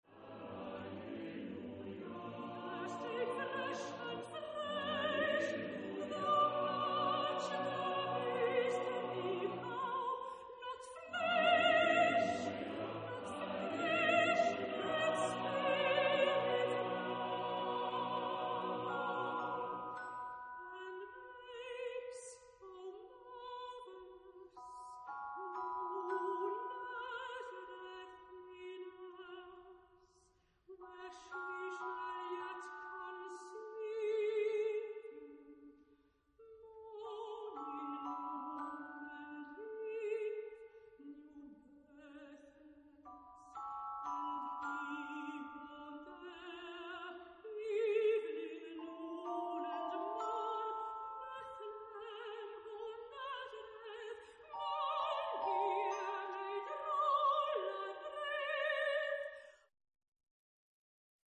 ... voor sopraan, vijfstemmig koor en celesta ...
Genre-Style-Forme : Sacré ; contemporain
Type de choeur : SSATB  (5 voix mixtes )
Solistes : Soprano (1)  (1 soliste(s))
Instruments : Celesta (1)